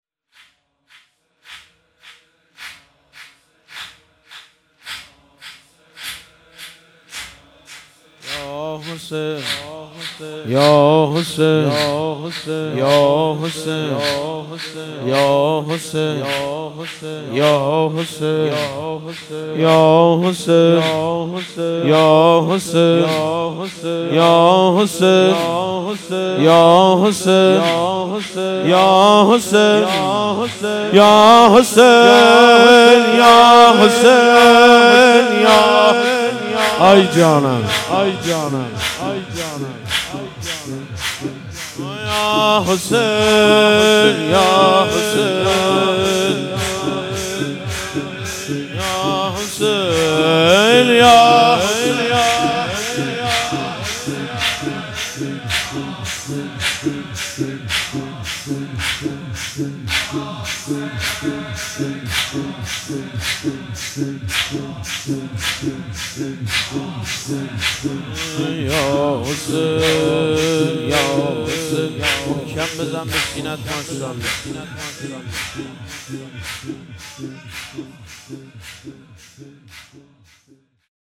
مراسم هفتگی/22آذر97